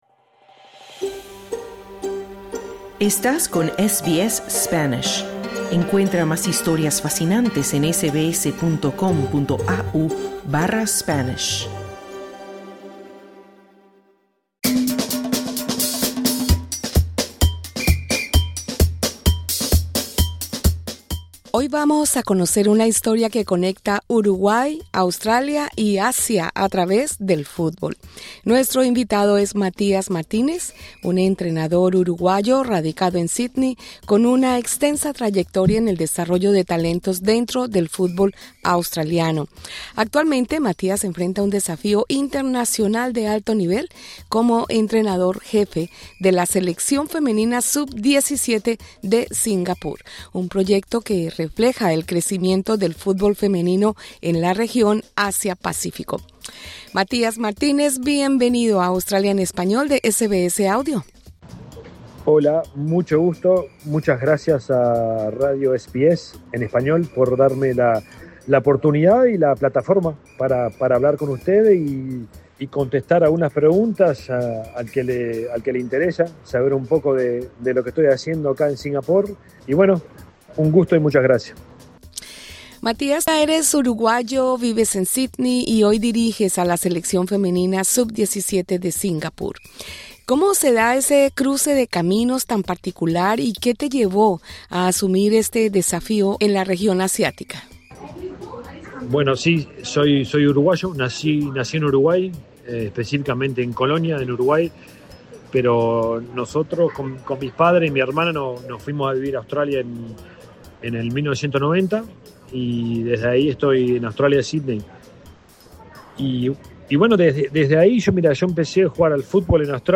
habla con SBS Audio sobre detección de talentos, formación de jugadoras y las diferencias entre el desarrollo del fútbol femenino en Australia y Singapur.